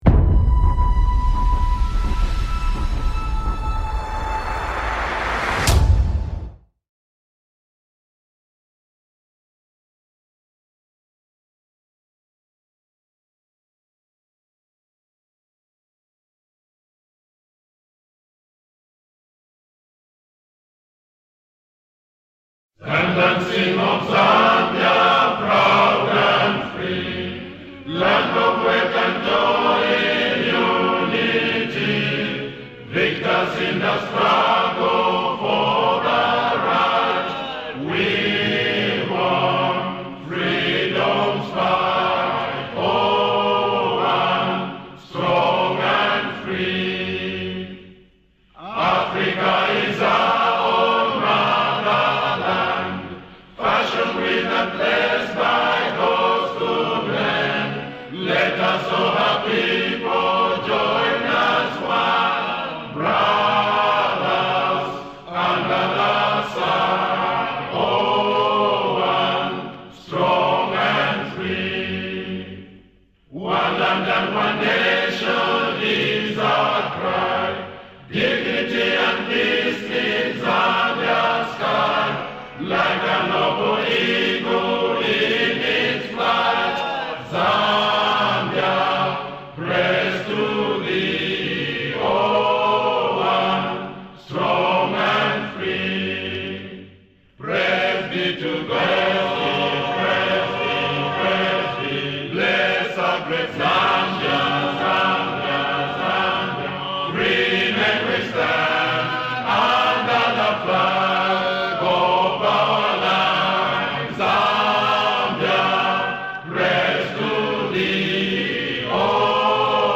national-anthem-of-zambia-english-lyrics.mp3